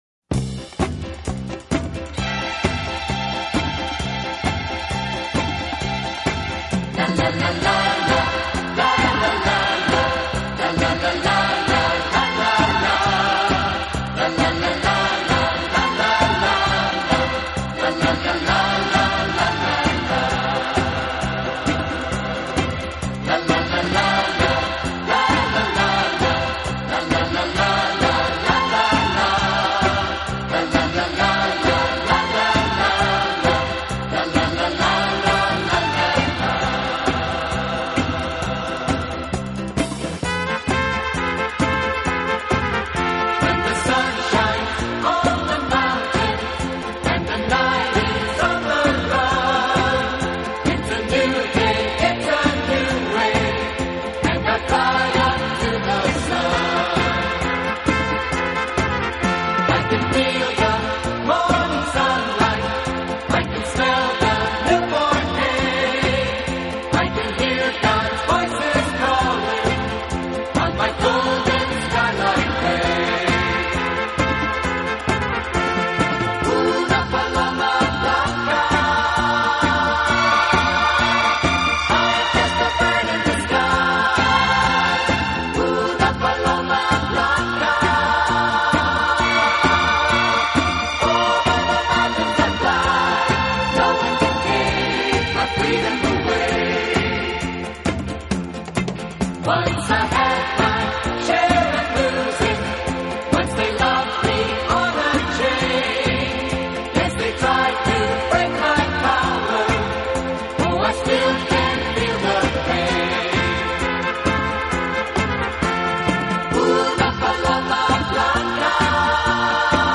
【轻音乐专辑】
他在60年代以男女混声的轻快合唱，配上轻松的乐队伴奏，翻唱了无数热